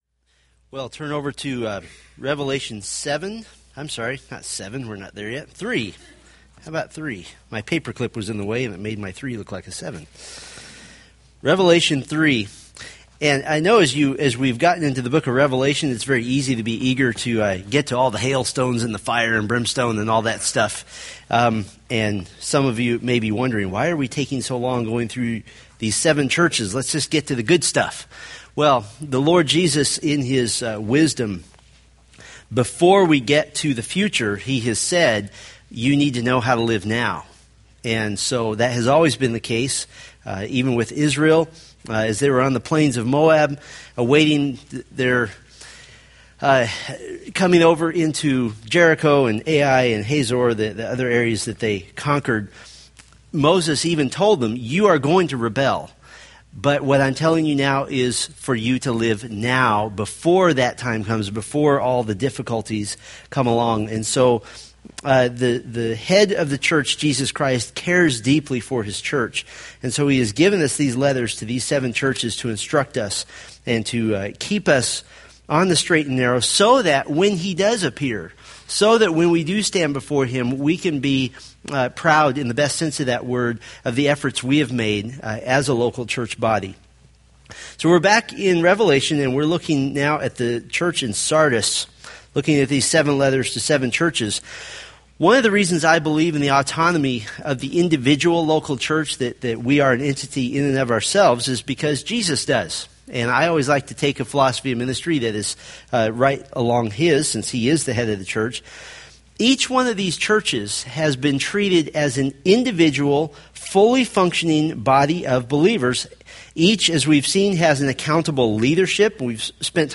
From the Revelation sermon series.